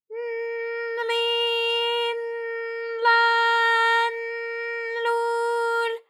ALYS-DB-001-JPN - First Japanese UTAU vocal library of ALYS.
l_n_li_n_la_n_lu_l.wav